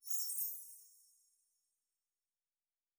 Fantasy Interface Sounds
Magic Chimes 09.wav